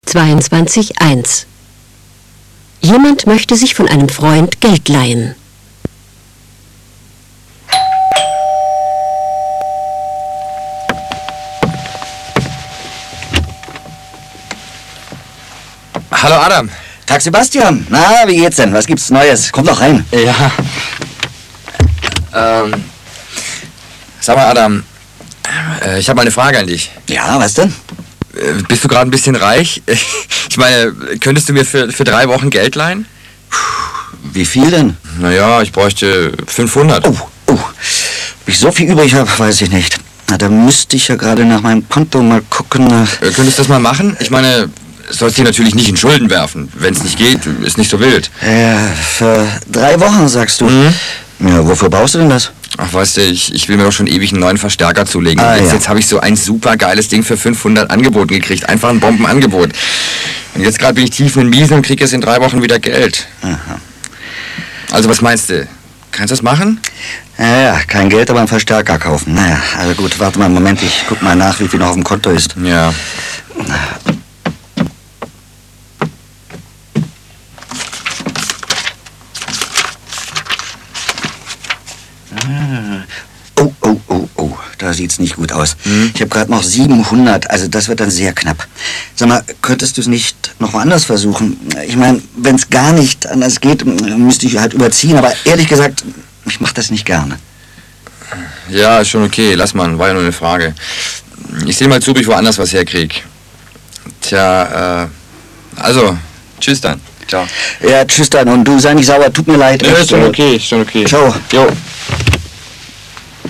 1.) Im folgenden Dialog sprechen zwei Deutsche: